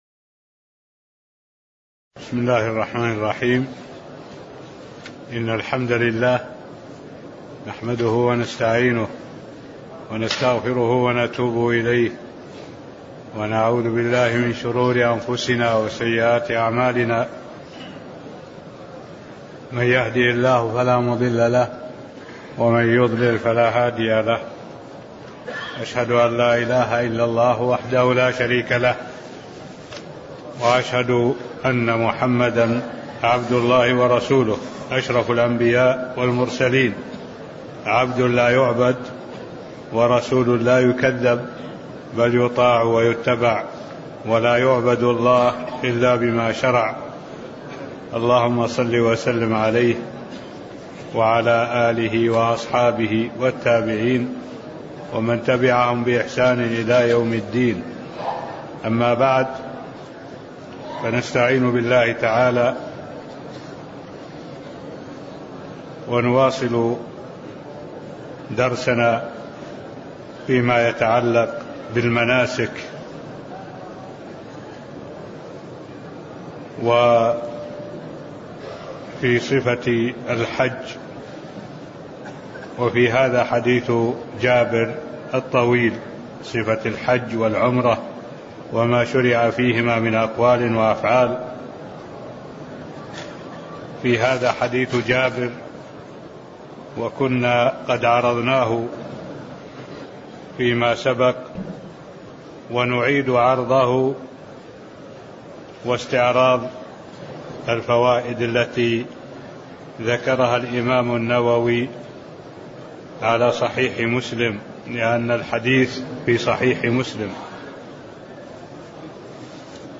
المكان: المسجد النبوي الشيخ: معالي الشيخ الدكتور صالح بن عبد الله العبود معالي الشيخ الدكتور صالح بن عبد الله العبود تكملة حديث جابر في حجة الوداع (08)